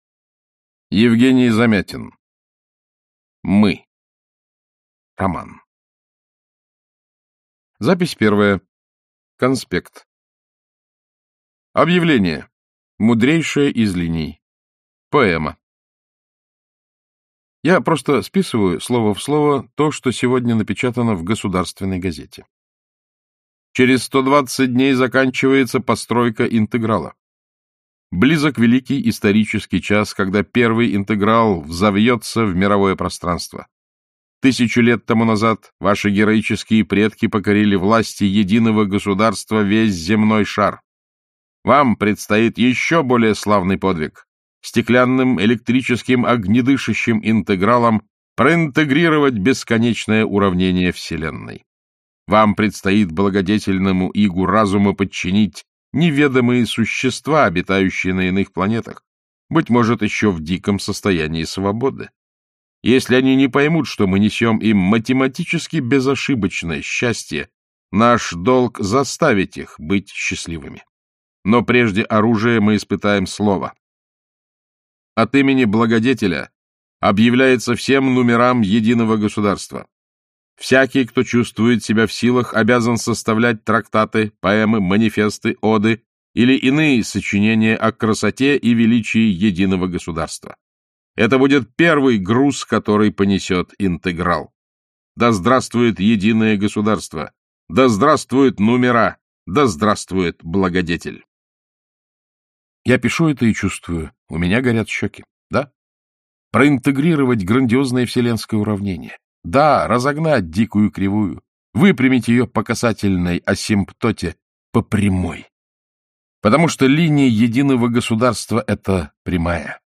Аудиокнига Мы | Библиотека аудиокниг